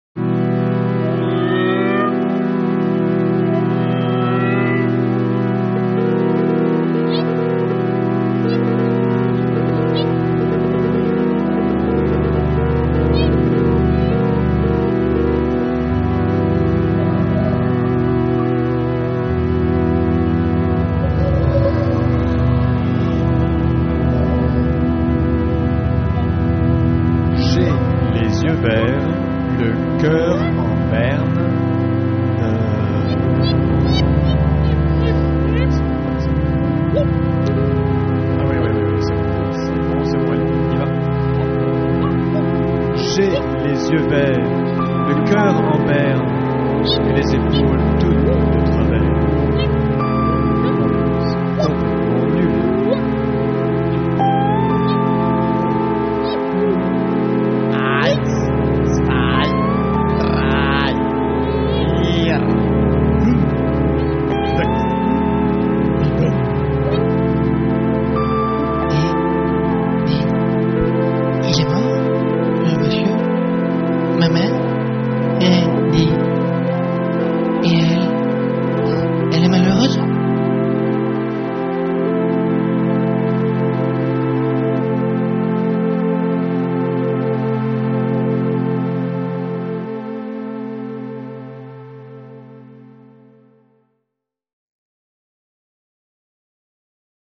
Favourably nordic.